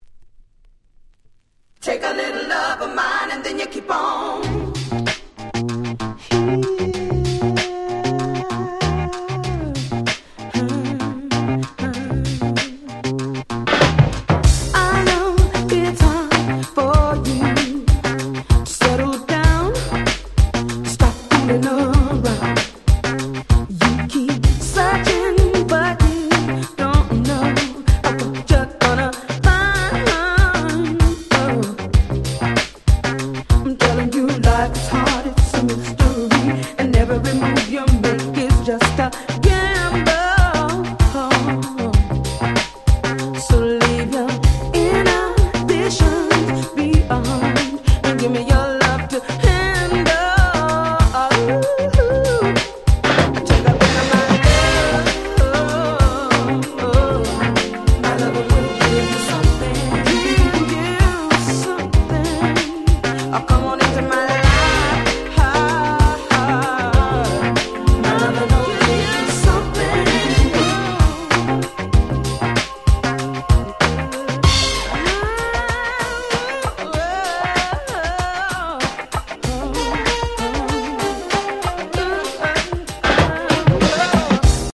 97' Nice Neo Soul / R&B !!
土臭くもGroovyな最高の仕上がり！！